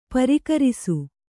♪ parikarisu